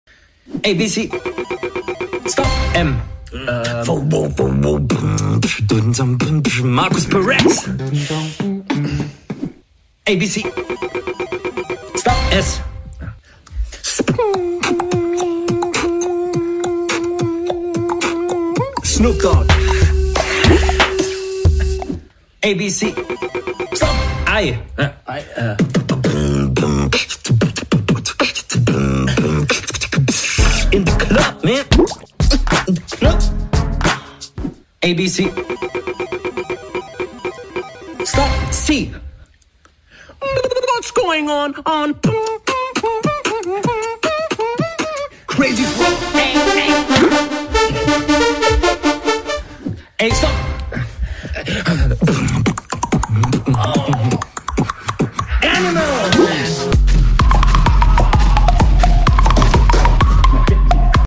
Abc Beatbox Battle